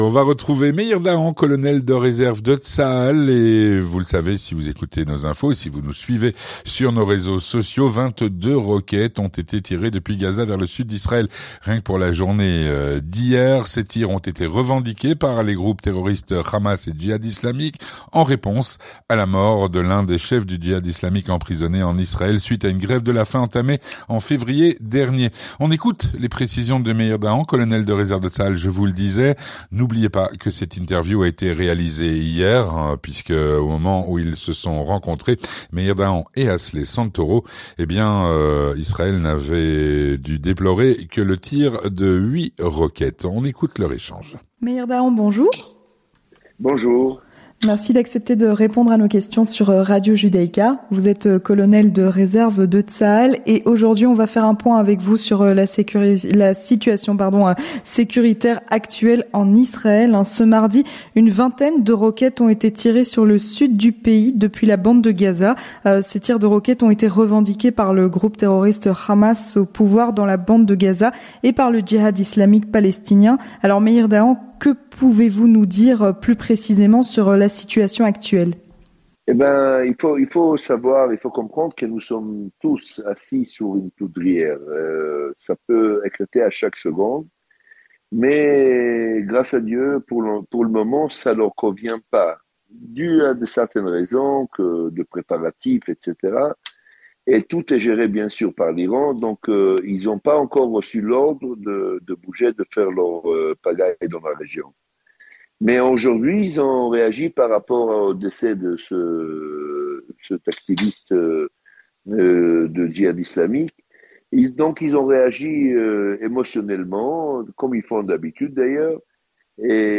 Entretien du 18h - La situation sécuritaire en Israël